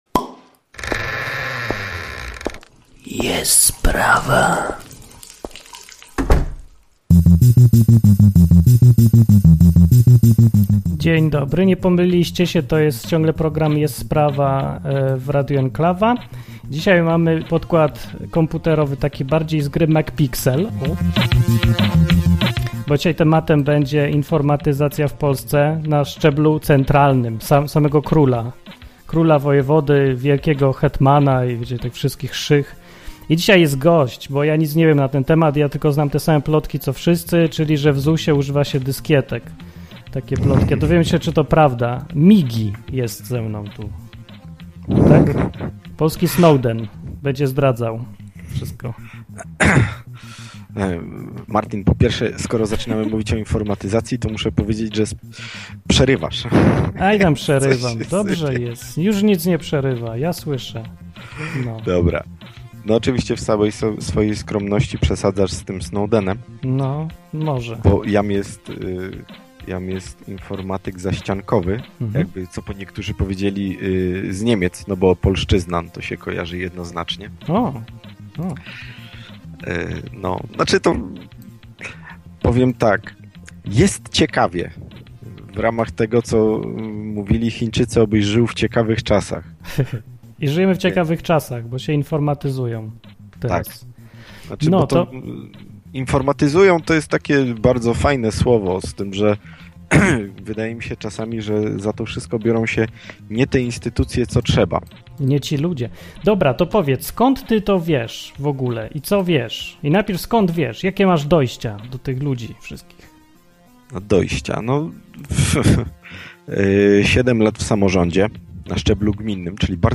Rozmawiamy z gościem o tym jak wygląda od środka informatyzacja w Polsce na szczeblu centralnym. Przy okazji testujemy ePuap i inne rządowe serwisy.